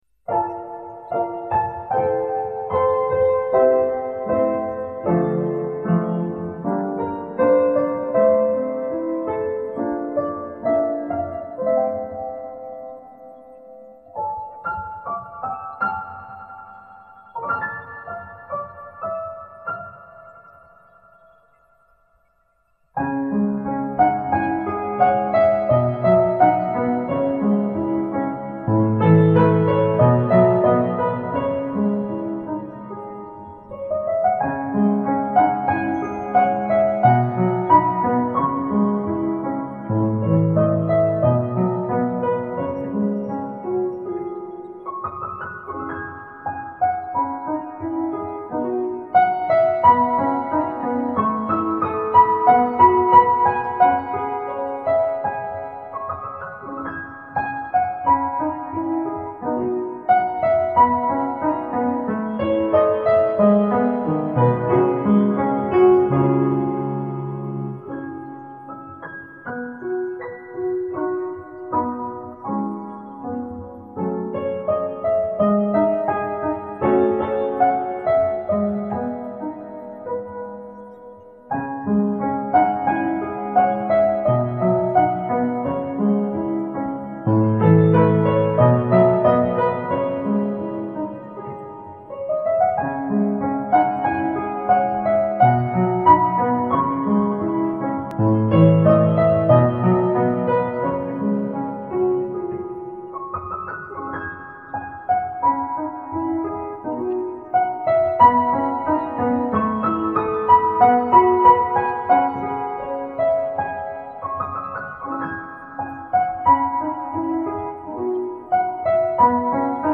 对比原来的曲子才发现自己弹的速度不对，谁有办法在不改变音高的前提下把速度加快？
几块钱买的烂话筒，根本就听不出来强弱= =。。。原始版更可怕，都是电流滋滋拉拉的声音
当时是硬弹，一下午抠出来，现在让我弹也弹不出。
楼上，这速度唱出来会把人郁闷死的